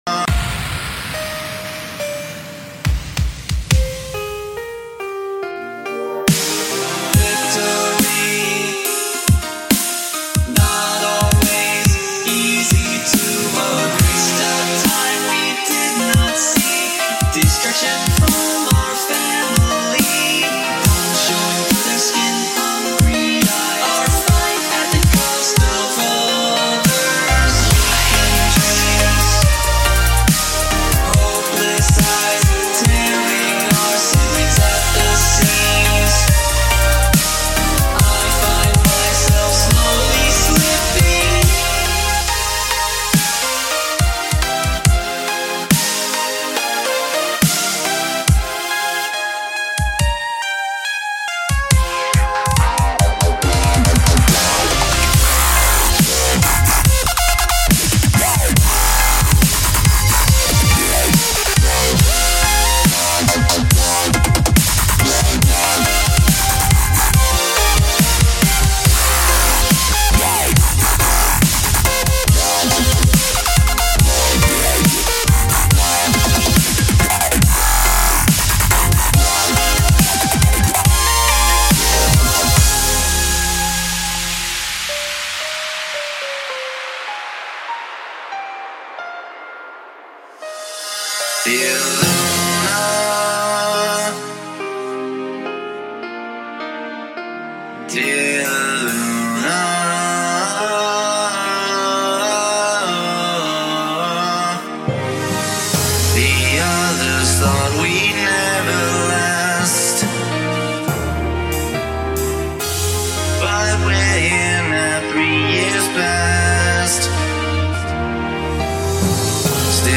BPM - 140
Genre - Dubstep/Orchestral